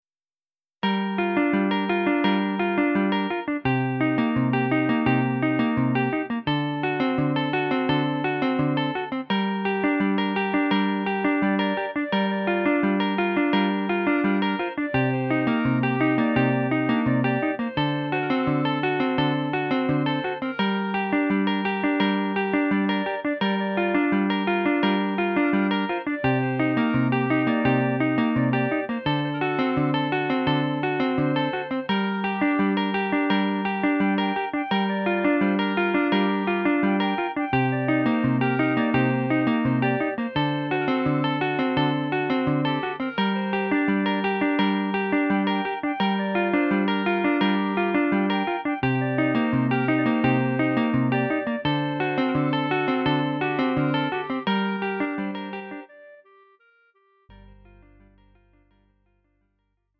음정 원키 3:26
장르 pop 구분 Lite MR